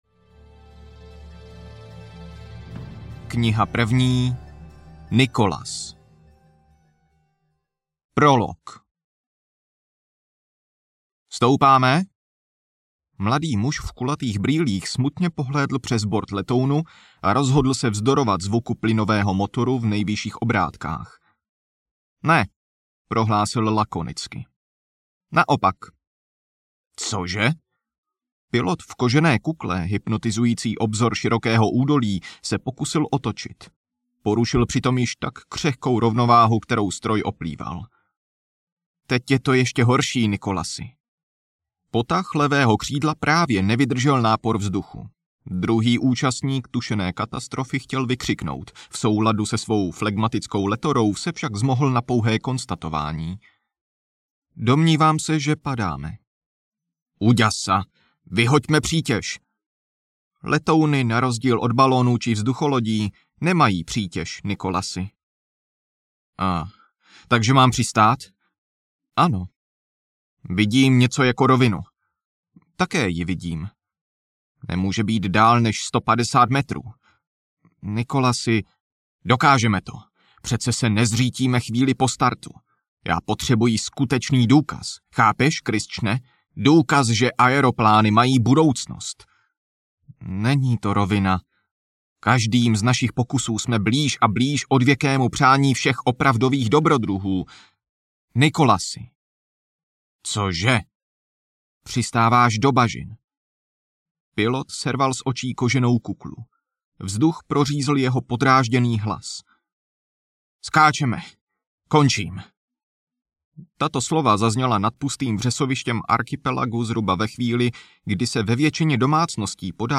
Pelyněk: Díl 1 audiokniha
Ukázka z knihy